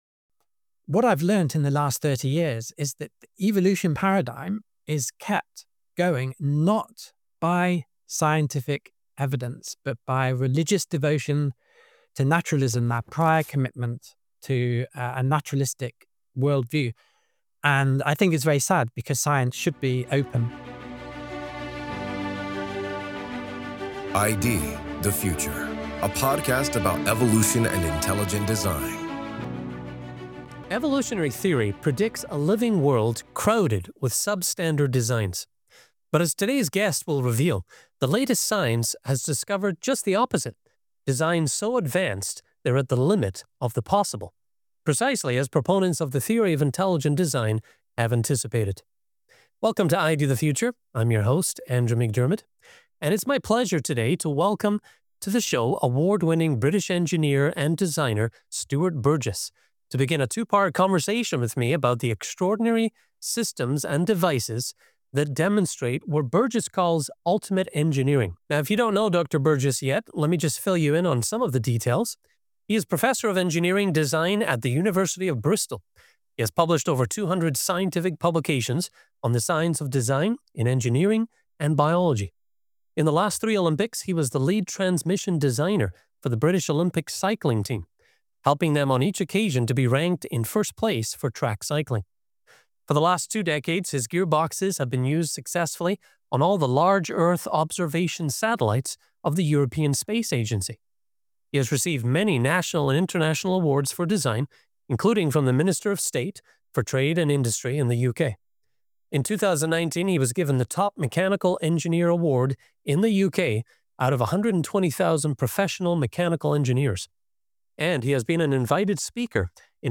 Ultimate Engineering: An Interview